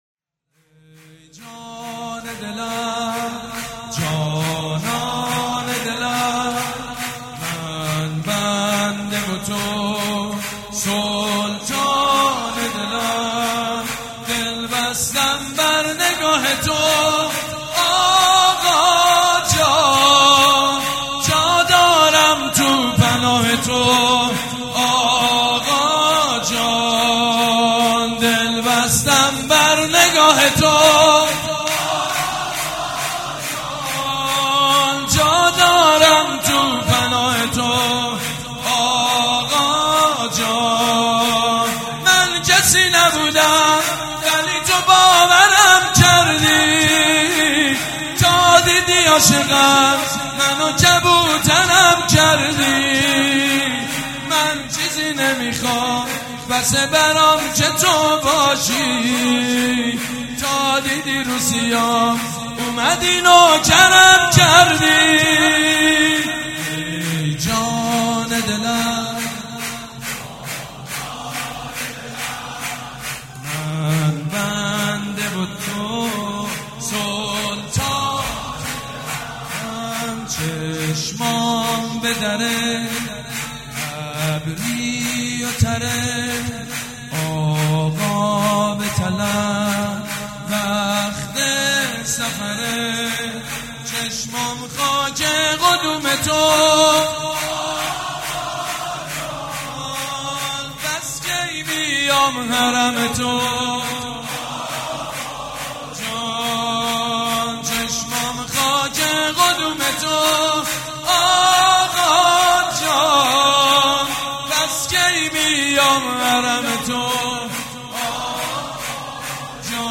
«میلاد امام رضا 1397» شور: ای جان دلم جانان دلم
«میلاد امام رضا 1397» شور: ای جان دلم جانان دلم خطیب: سید مجید بنی فاطمه مدت زمان: 00:02:41